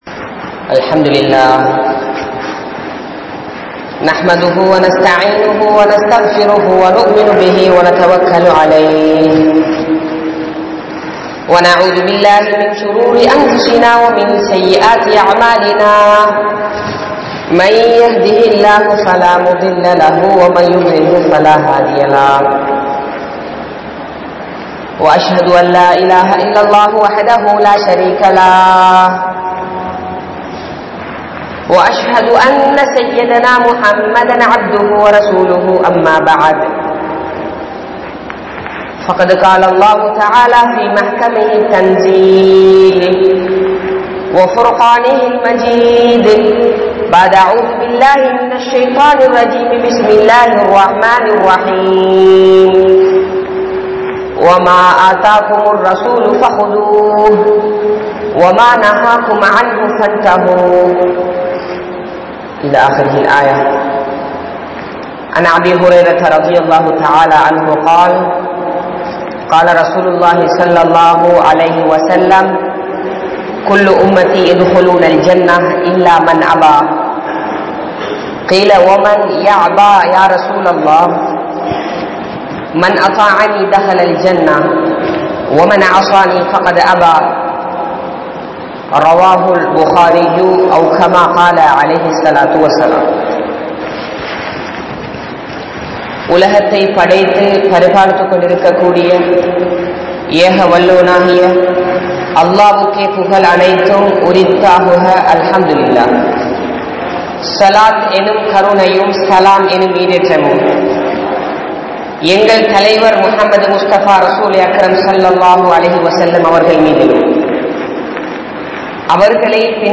Nabi(SAW)Avarhalin Vali Murai (நபி(ஸல்)அவர்களின் வழிமுறை) | Audio Bayans | All Ceylon Muslim Youth Community | Addalaichenai
Hudha Jumua Masjidh